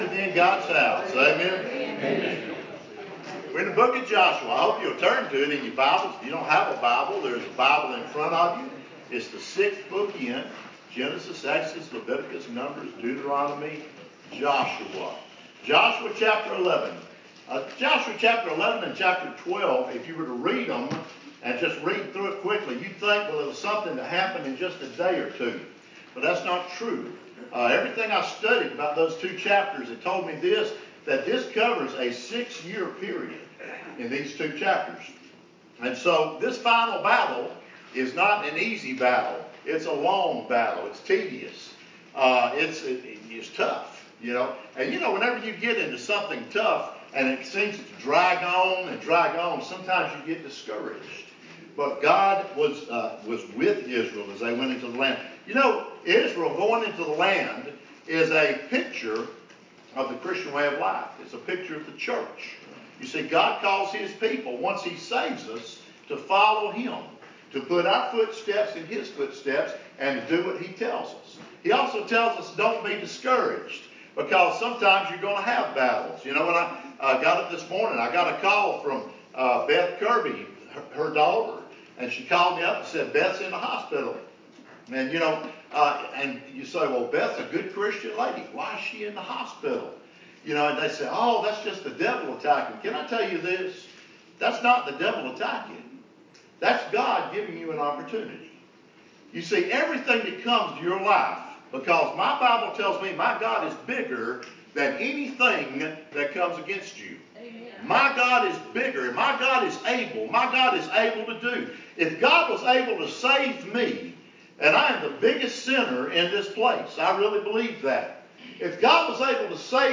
joshua-11-the-final-victory-riverview-830-am-svs-.mp3